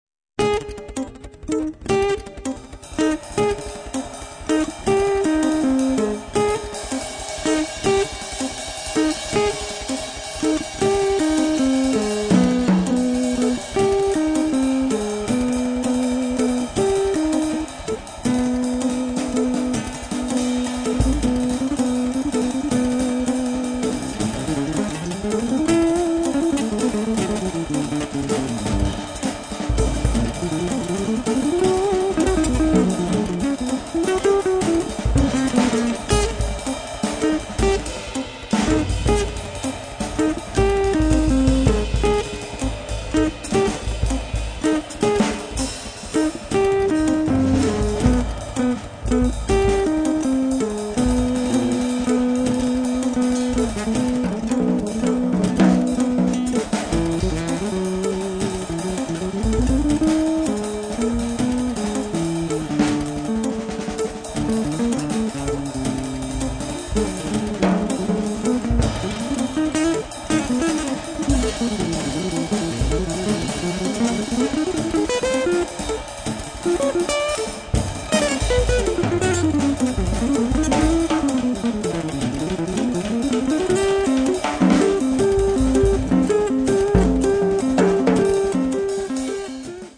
chitarra
batteria e percussioni